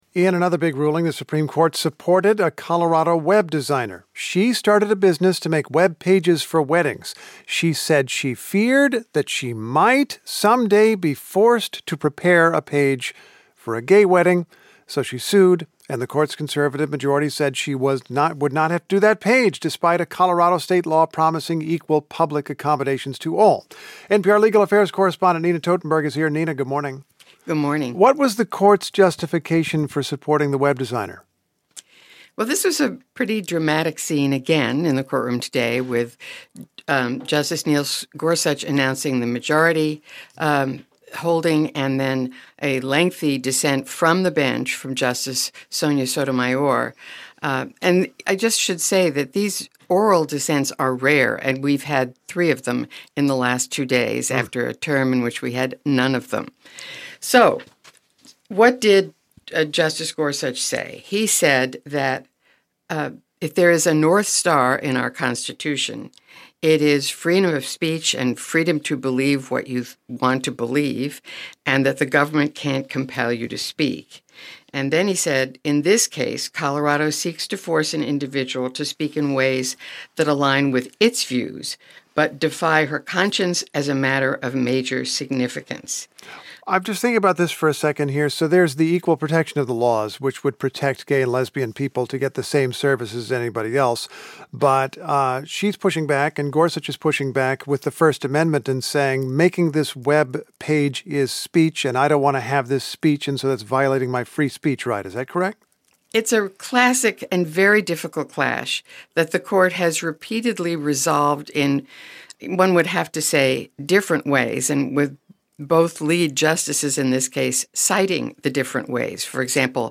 Justice Sonia Sotomayor read an impassioned dissent from the bench in a case where a majority of justices sided with a Colorado web designer who refused to create websites for same-sex weddings.